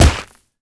punch_grit_wet_impact_03.wav